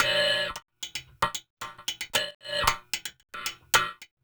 LQT WD HAT-R.wav